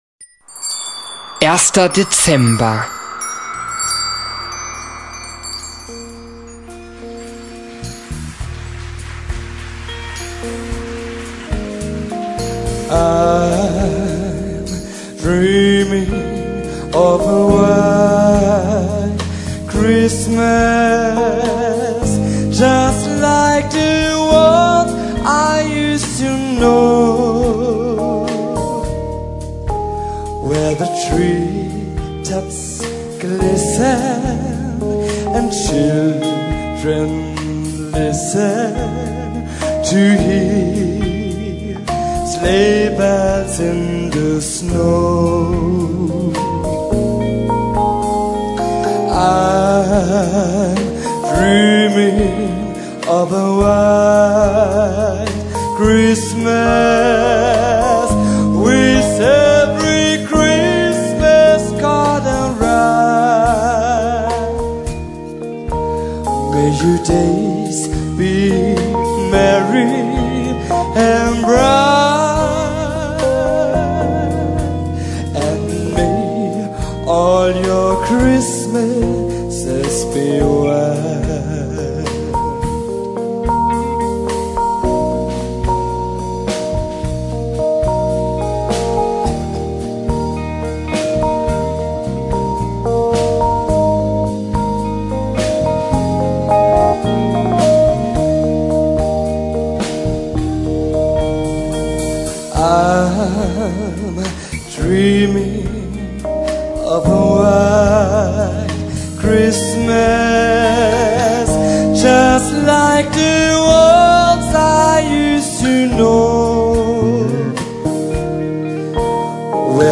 stapft dieser hörbar bei eisigem Wind durch den Schnee
knistert ein Feuer im Hintergrund
liest er sehr berührend vor
eine jazzige Version